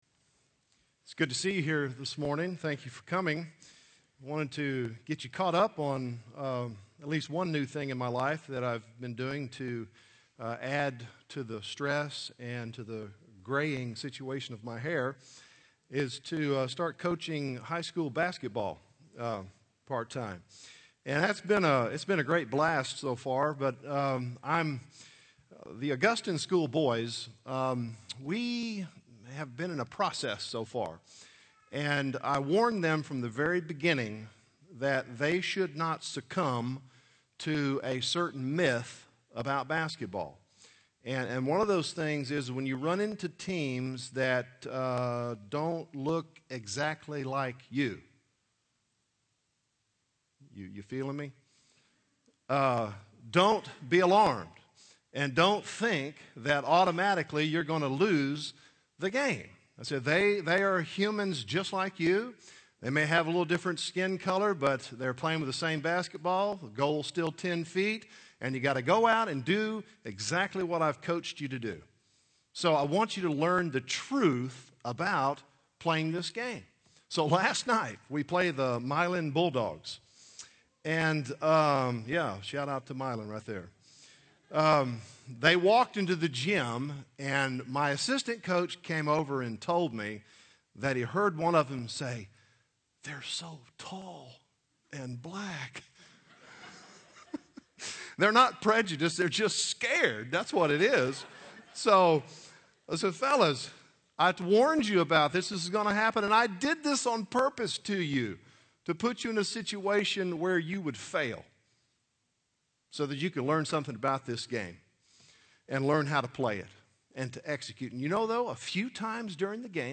Chapels
Address: "The Fast that Feeds" from Isaiah 58